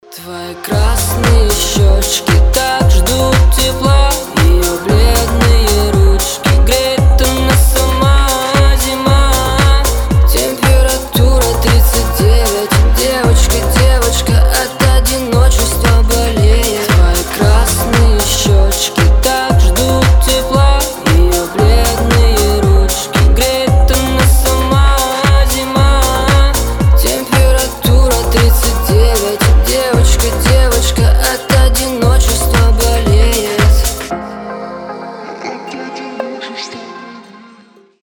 • Качество: 320, Stereo
ритмичные
русский рэп